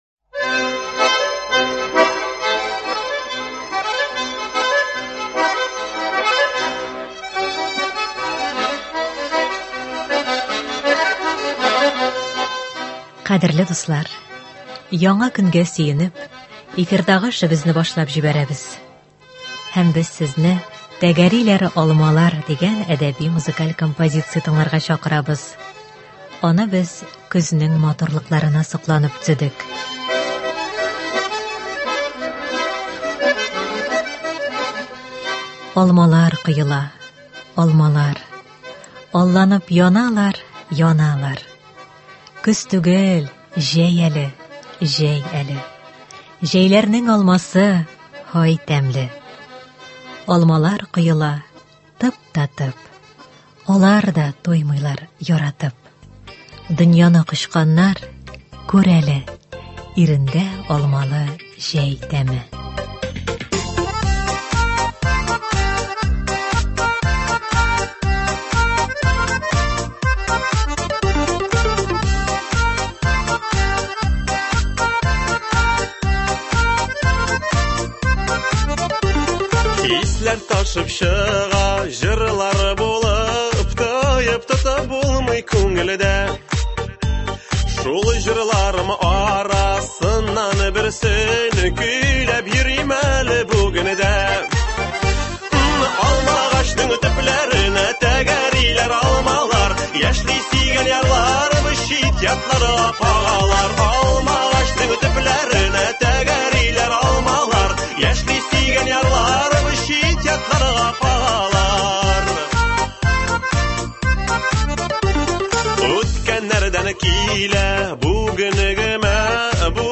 “Тәгәриләр алмалар…”. Әдәби-музыкаль композиция.